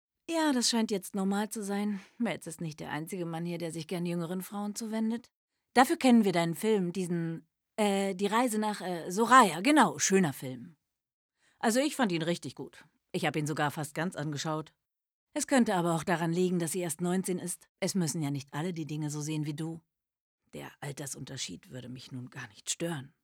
Synchron (lässig)